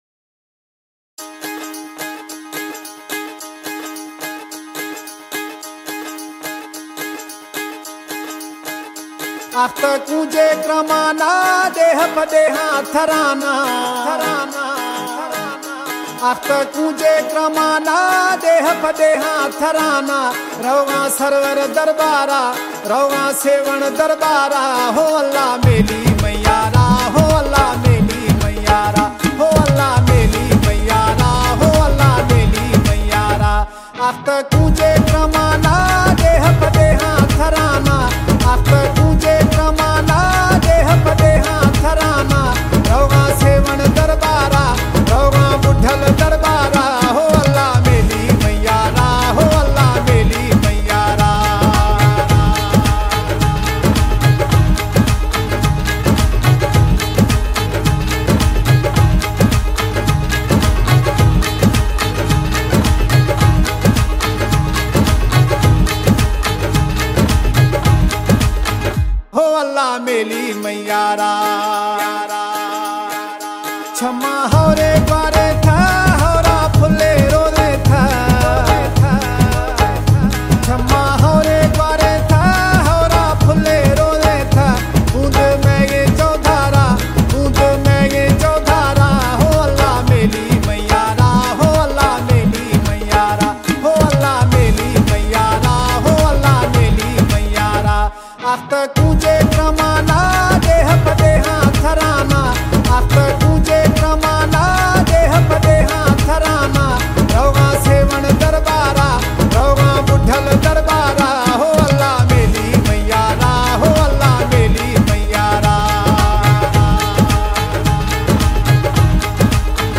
Genre: Balochi Song